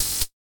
zap.ogg